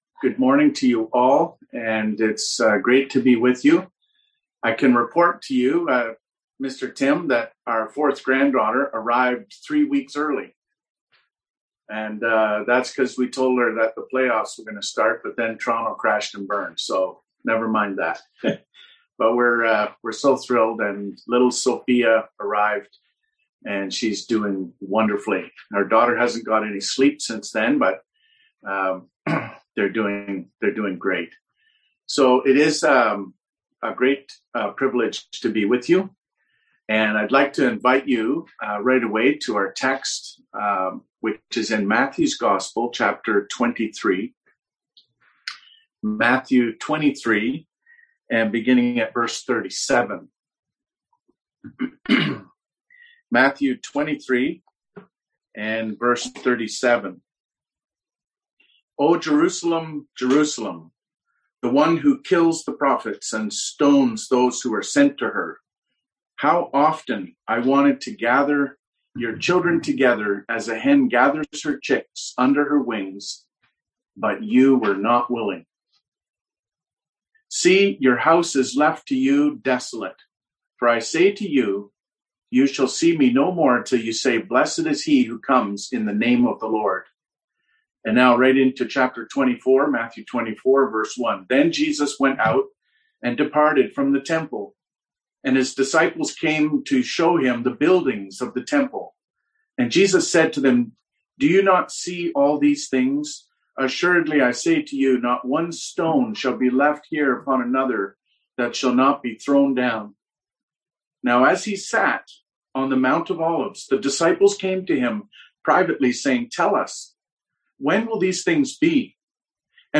Service Type: Sunday AM Topics: End Times , Prophecy